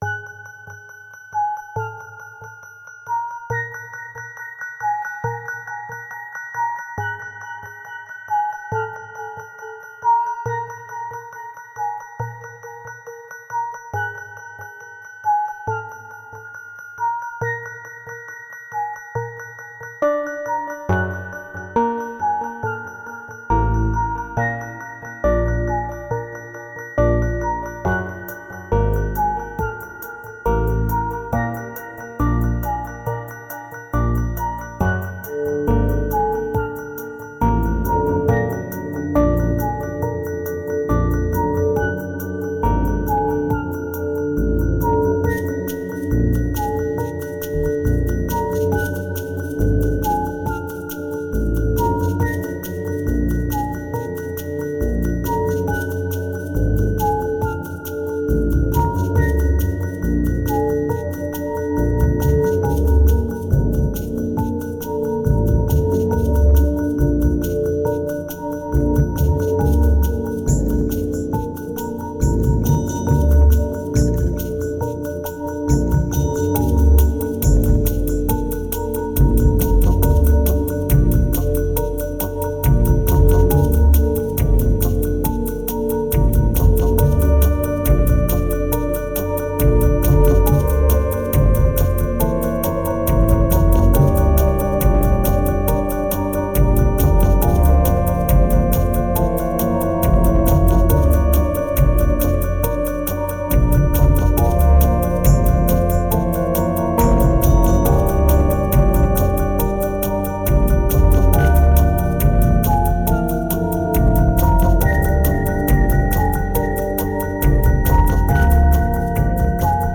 2294📈 - -10%🤔 - 69BPM🔊 - 2013-02-22📅 - -174🌟